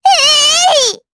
Estelle-Vox_Attack6_jp.wav